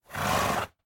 horse_idle1.ogg